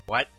Play, download and share Qwilfish What original sound button!!!!